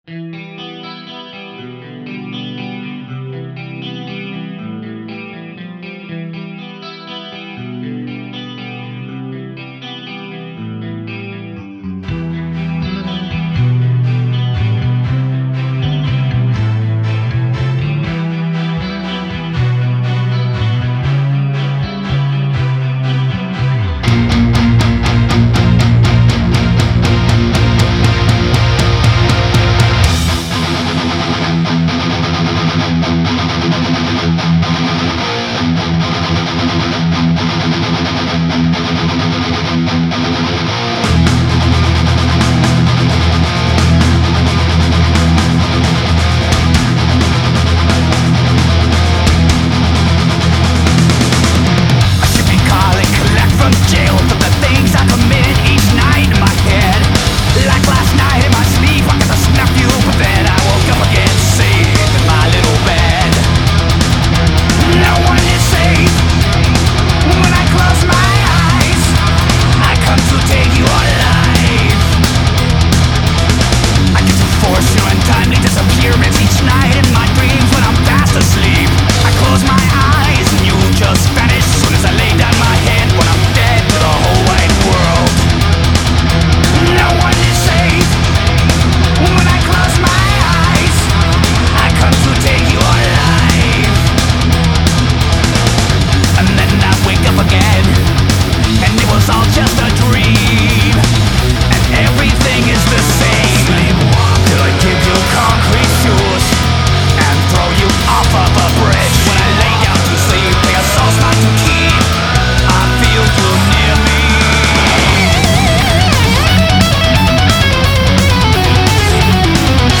风格：鞭笞金属，速度金属，重金属